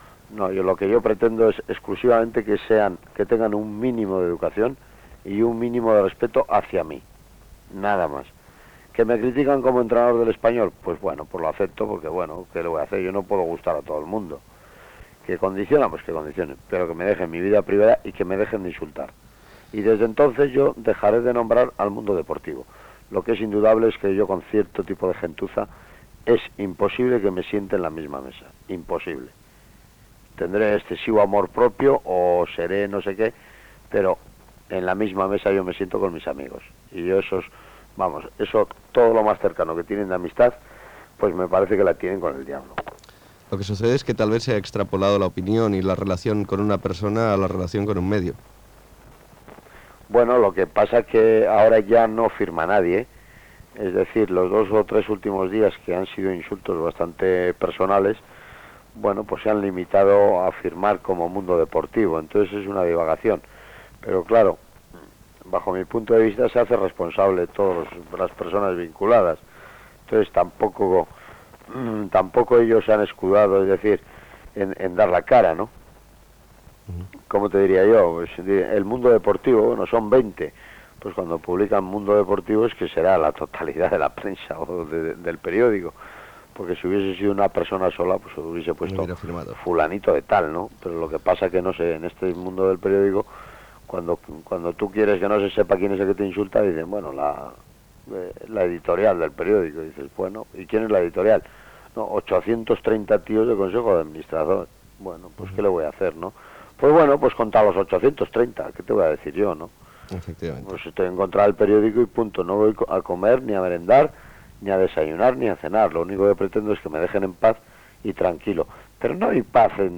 ffdfc51e4811c7379b92e5e7b2169aeeb19da3b9.mp3 Títol Ràdio 4 Emissora Ràdio 4 Cadena RNE Titularitat Pública estatal Descripció Entrevista a l'entrenador del R.C.D.Espanyol Javier Clemente sobre "El mundo deportivo" i els resultats de l'Espanyol. Crònica del partit Sabadell Figueres. Resultats dels equips de Lleida